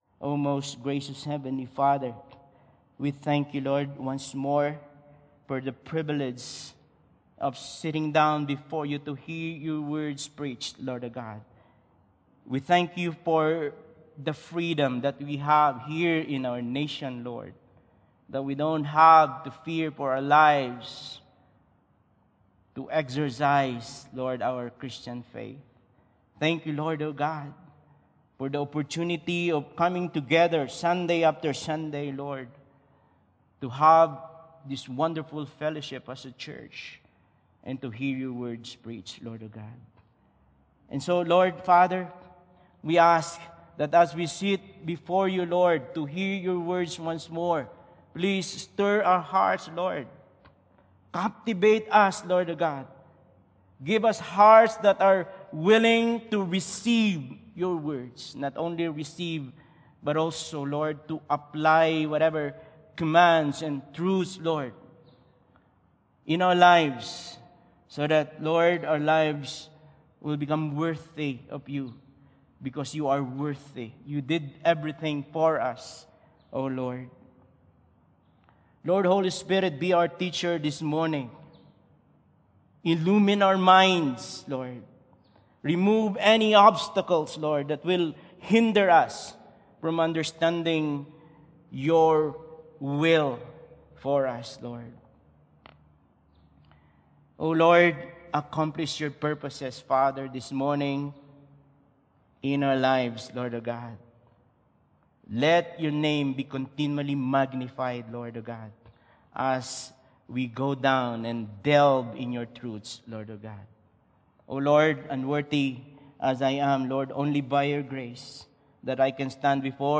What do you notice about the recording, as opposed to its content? WATCH AND BE BLESSED Finishing Strong 1 Videos February 23, 2025 | 9 A.M Service Finishing Strong | Acts 20:22-24 Information Information Download the Sermon Slides here.